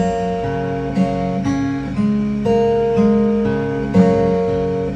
标签： 回火 女孩 孩子 悲伤 歌曲 故事
声道立体声